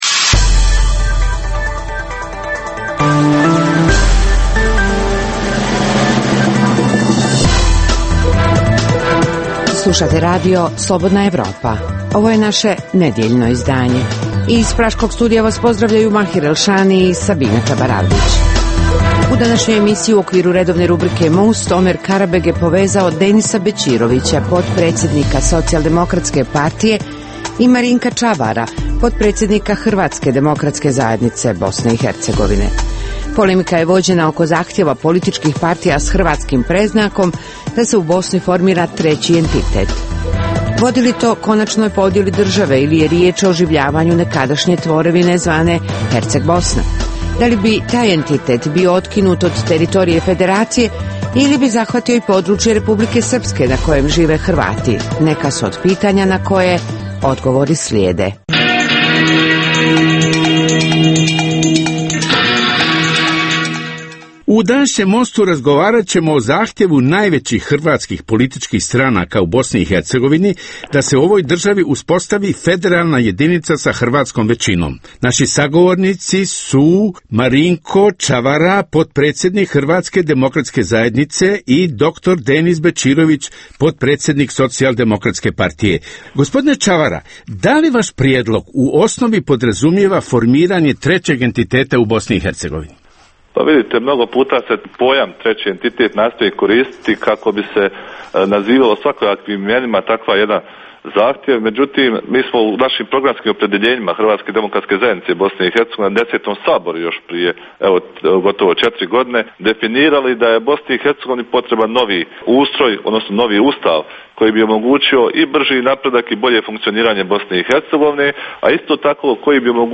u kojem ugledni sagovornici iz regiona diskutuju o aktuelnim temama.